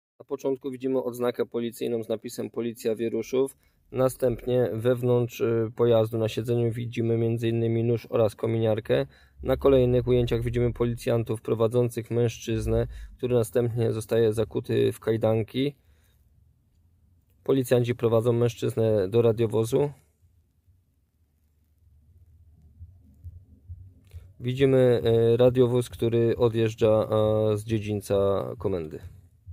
Nagranie audio audiodeskrypcja_do_filmu_zatrzymany.m4a